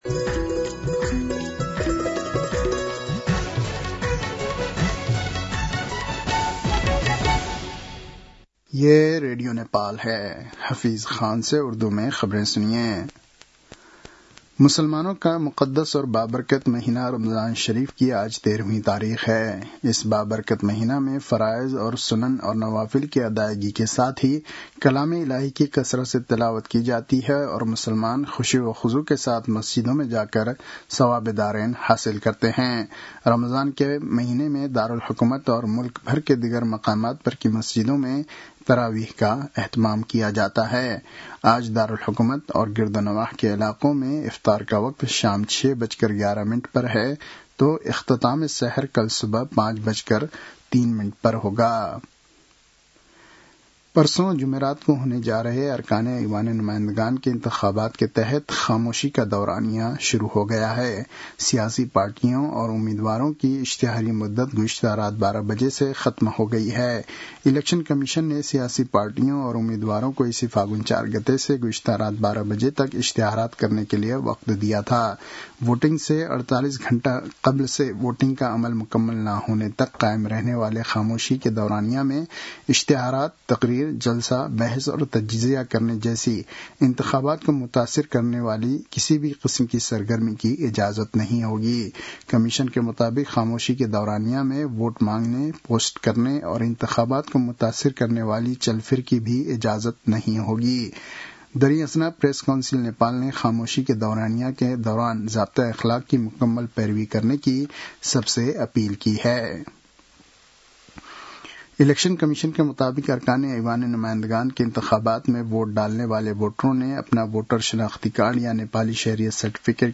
उर्दु भाषामा समाचार : १९ फागुन , २०८२